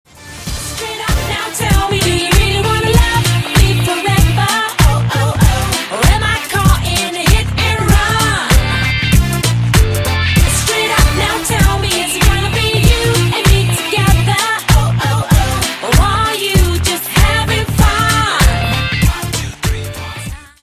• Качество: 128, Stereo
поп
Dance Pop
ретро